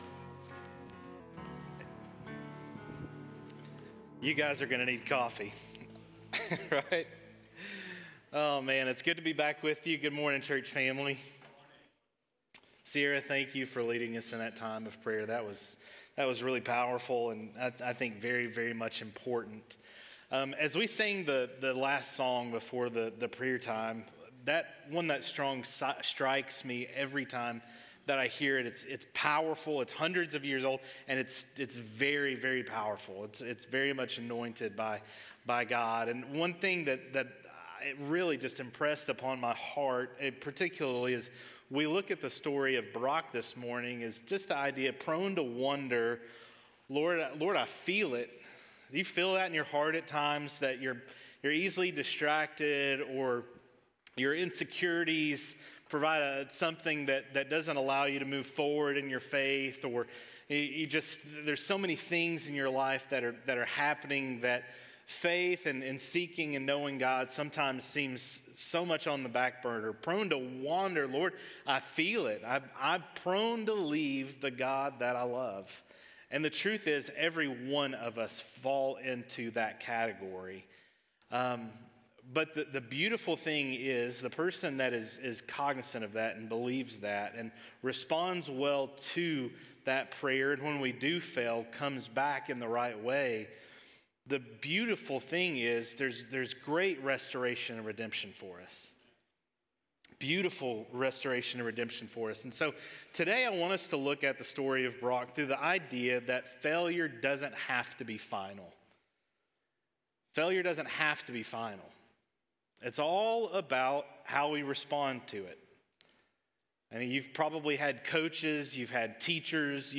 August-6-Sermon.mp3